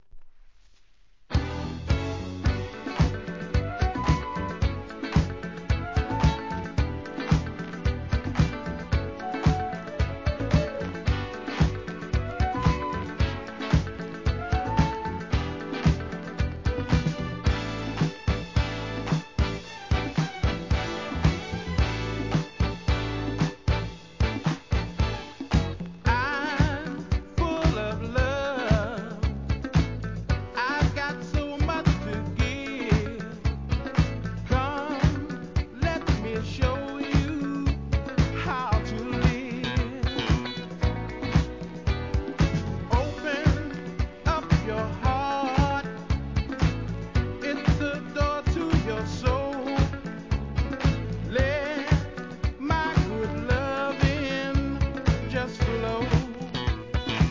¥ 2,750 税込 関連カテゴリ SOUL/FUNK/etc...